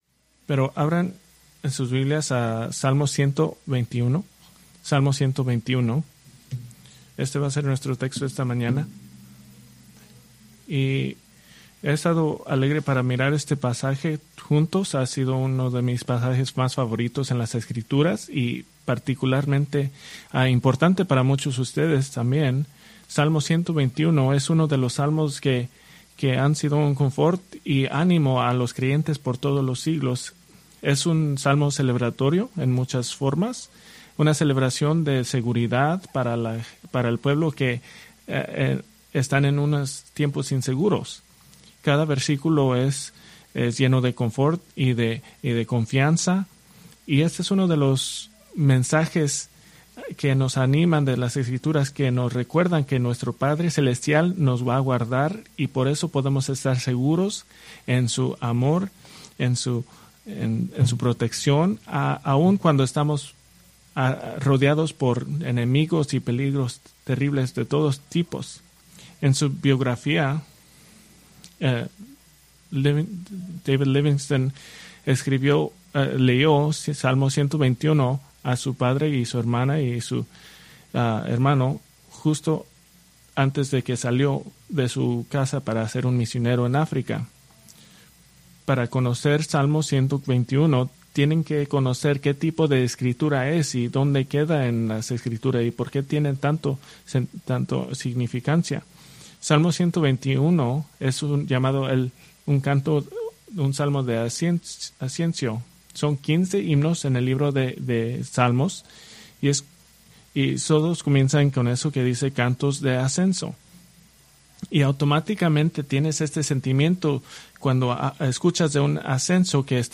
Preached August 18, 2024 from Salmo 121